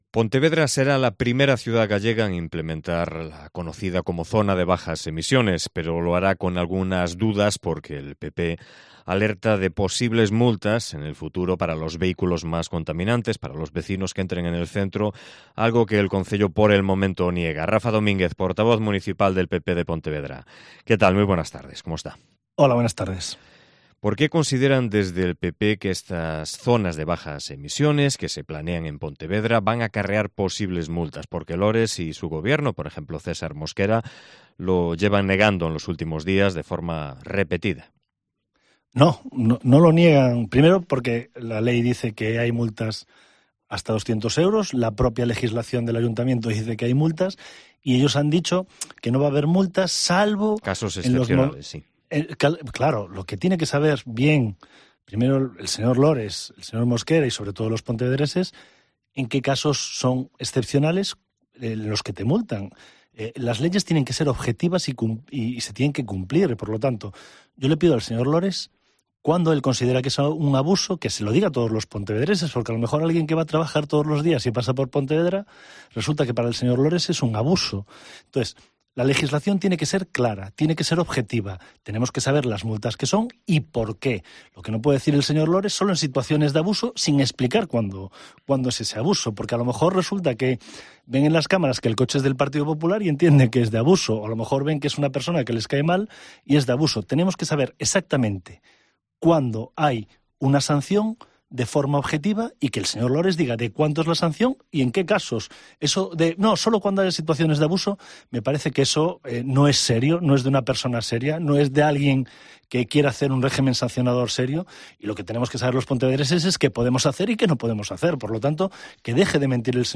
Entrevista con Rafa Domínguez, líder del PP de Pontevedra
AUDIO: Entrevista patrocinada por el Grupo Municipal del Partido Popular